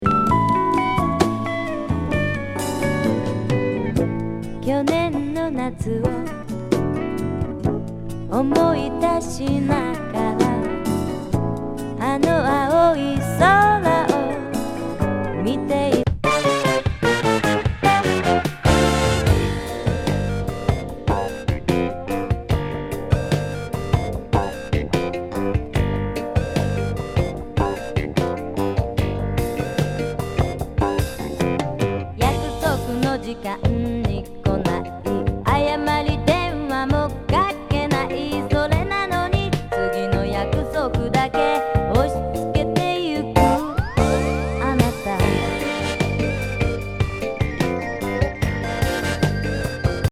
女性SSW 77年1ST。アンニュイ・メロウ